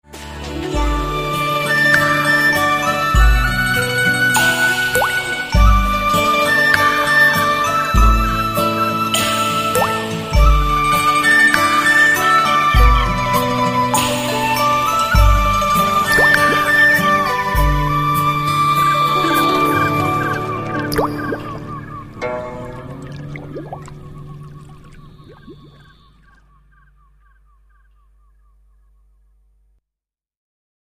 Thể loại nhạc chuông: Nhạc không lời